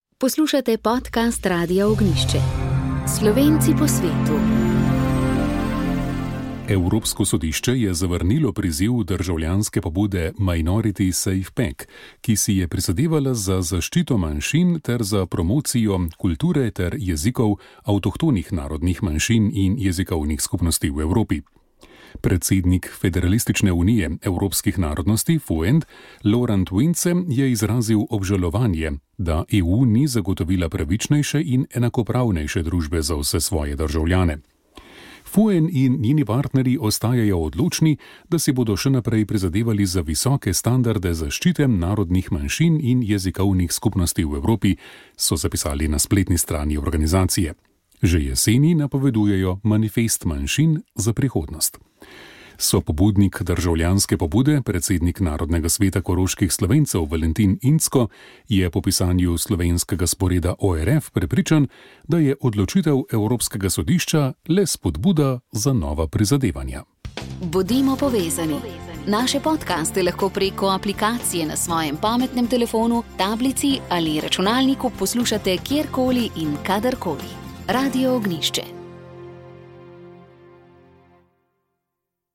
Doživetja narave so bila prepletena z našo terensko akcijo in lepimi jesenskimi barvami, ki smo jih prinesli k vam z višine malo pod 2000 metri. V drugem delu pa smo posvetili pozornost naravnemu dragulju Julijskih Alp, naši najvišji cesti in pregovoru Kdor seje ceste, žanje promet.